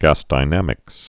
(găsdī-nămĭks)